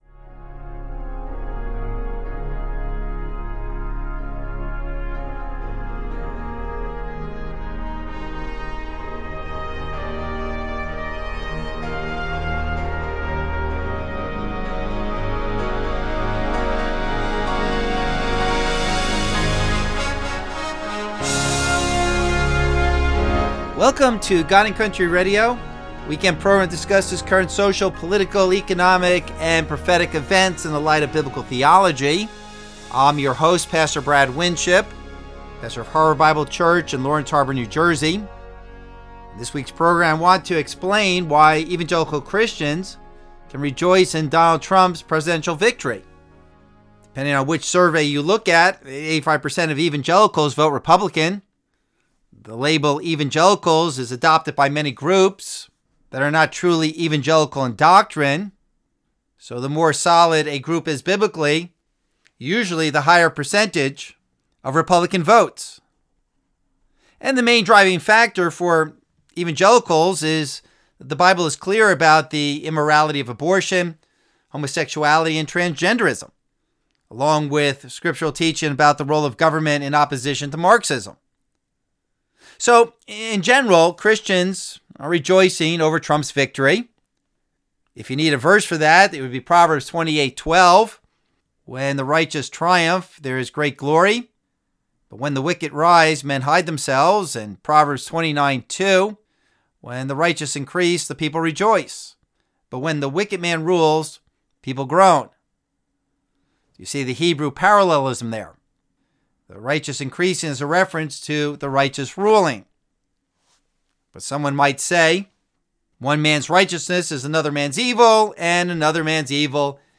Abridged Radio Program